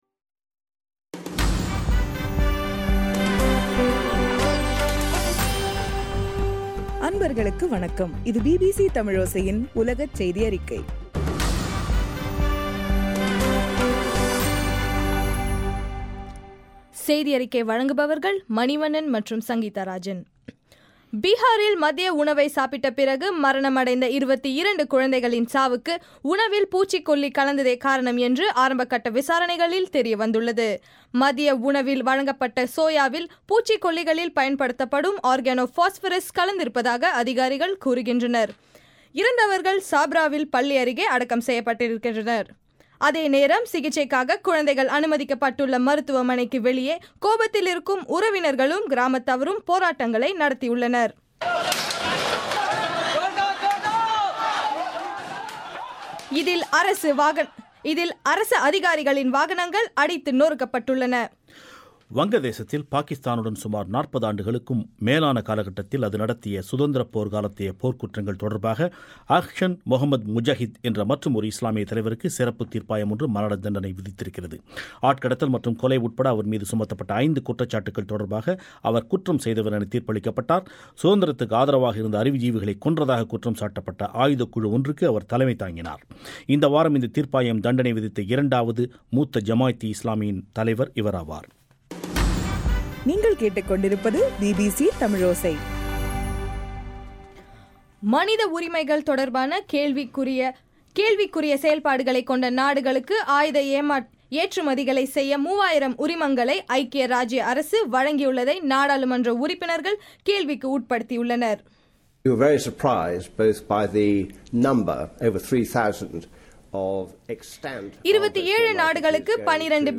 ஜூலை 17 2013 பிபிசி தமிழோசை உலகச் செய்தி அறிக்கை